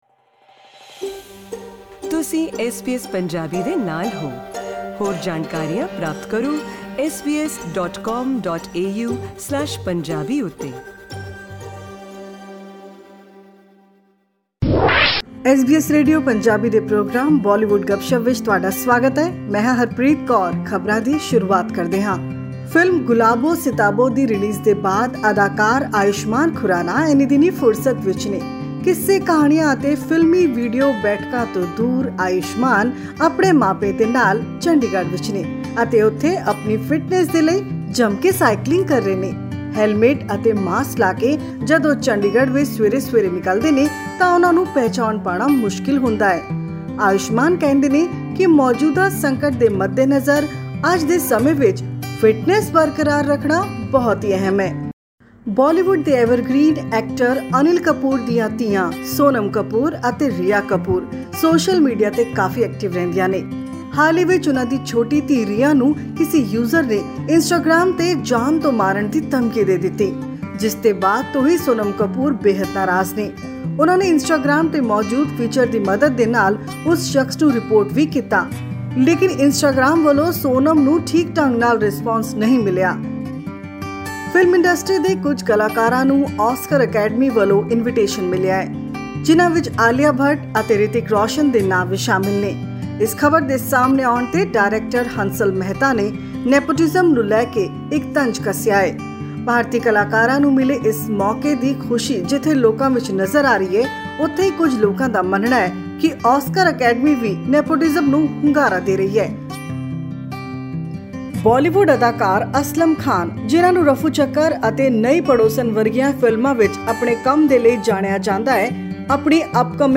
Find out which Bollywood bigwigs are in the news these days and listen to some latest music clips in our weekly Bollywood roundup.